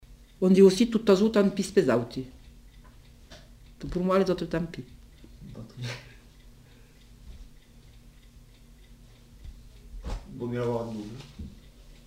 Lieu : Cathervielle
Effectif : 1
Type de voix : voix de femme
Production du son : récité
Classification : proverbe-dicton